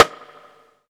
Snares
RIMSH.wav